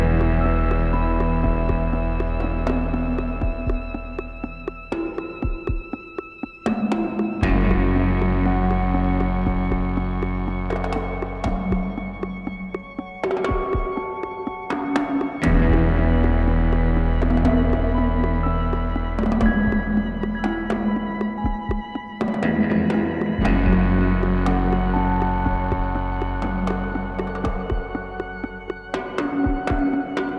Ens.voc & instr.